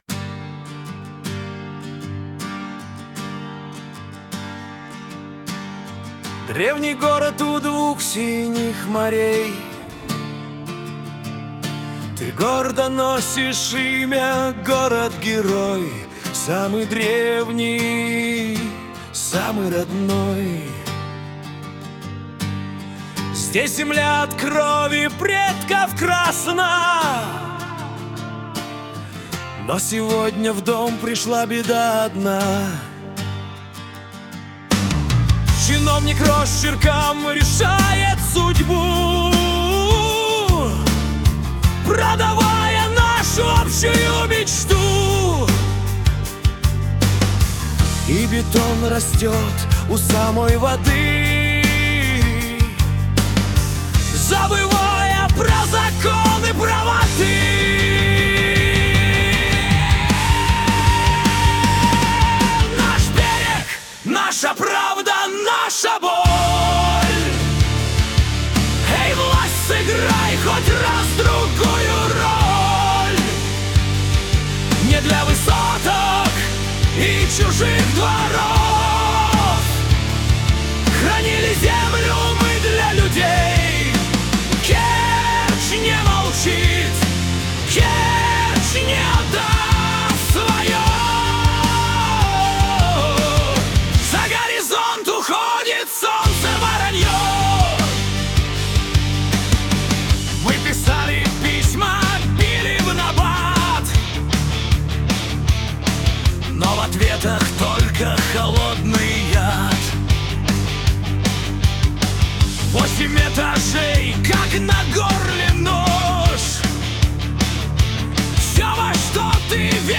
Авторы при помощи ИИ создали песню, в которой отражены переживания и надежды горожан.